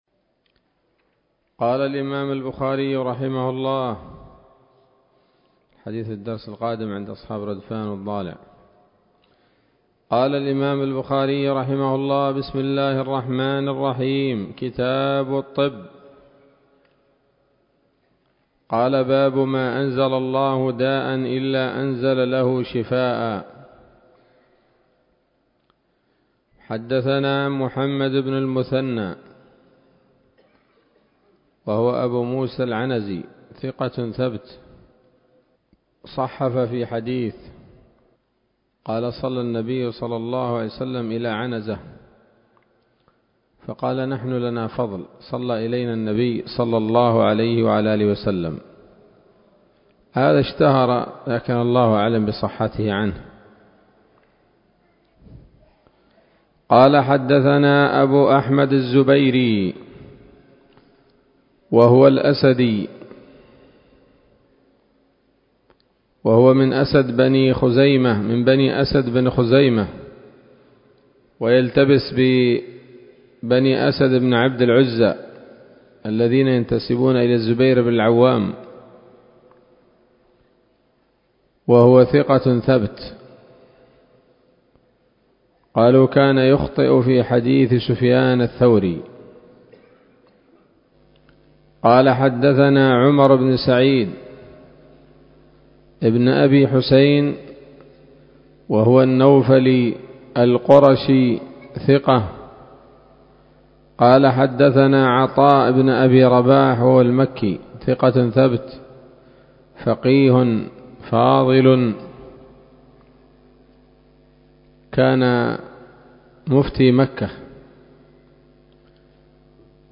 الدرس الأول من كتاب الطب من صحيح الإمام البخاري